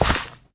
gravel2.ogg